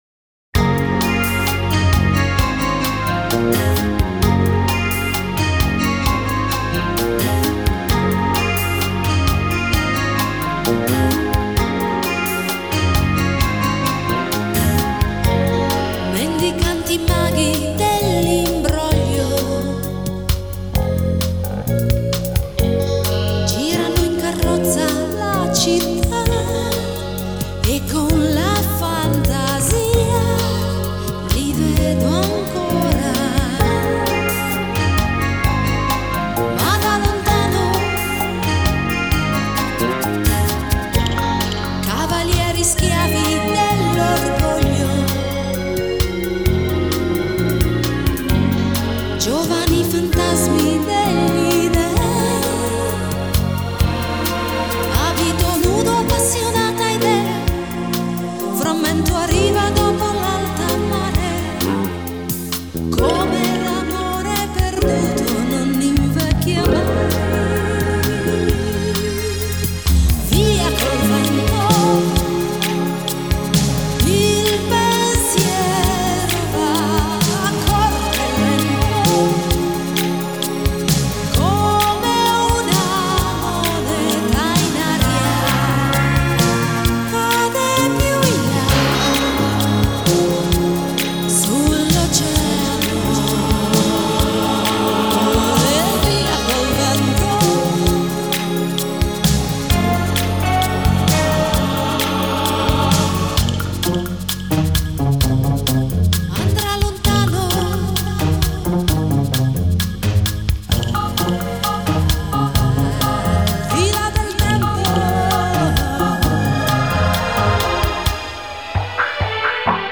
Genre: Pop, Pop-Rock, New Wave, Electronic